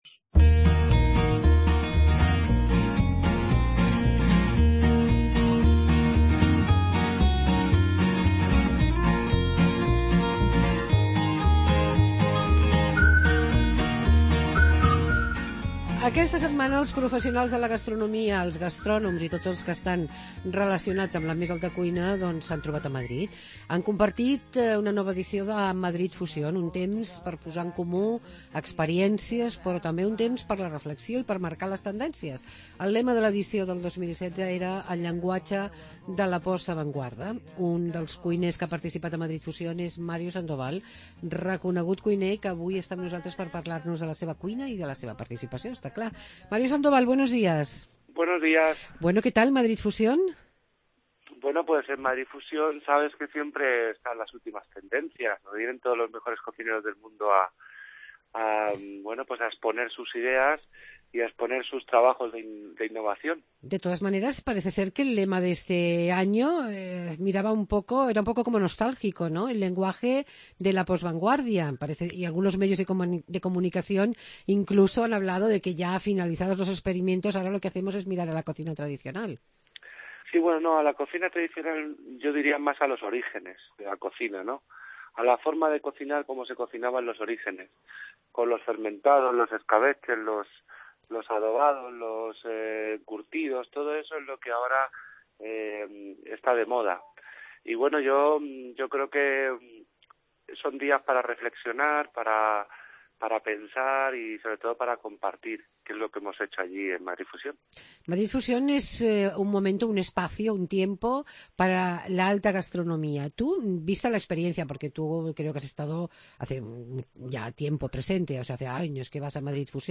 Madrid Fusión: Entrevista a Mario Sandoval